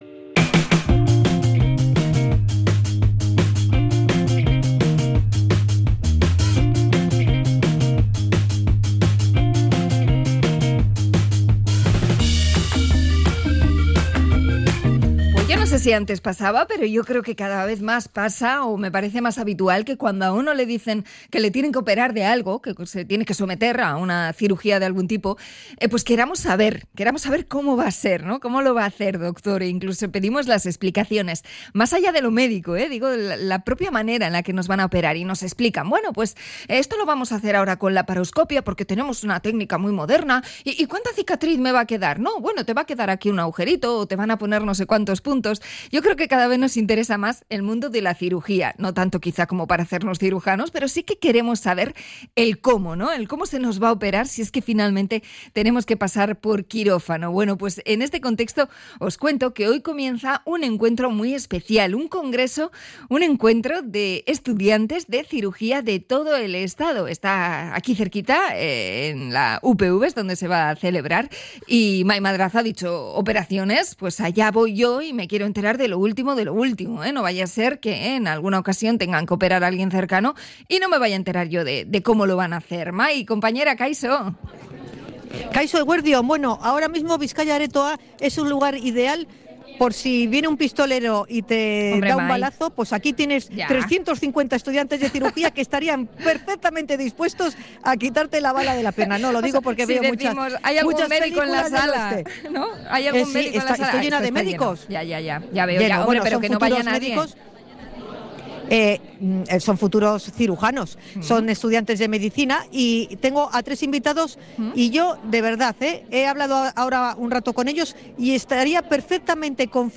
Hablamos con los organizadores del Congreso COCIE